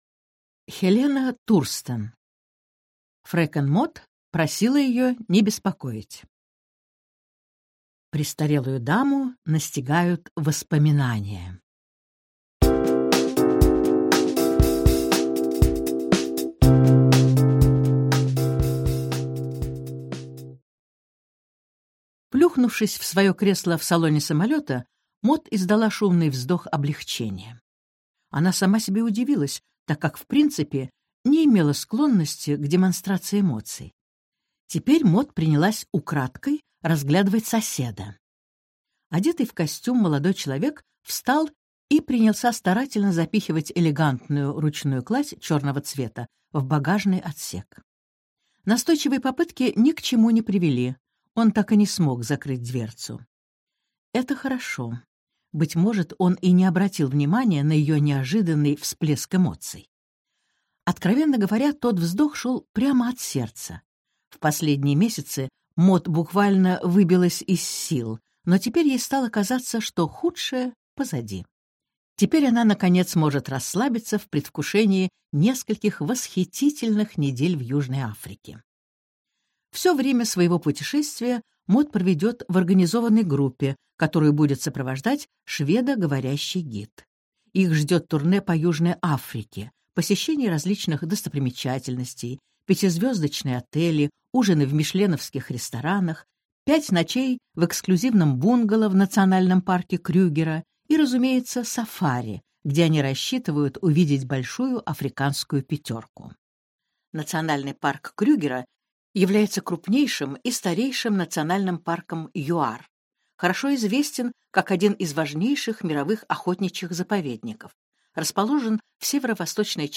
Аудиокнига Фрекен Мод просила ее не беспокоить | Библиотека аудиокниг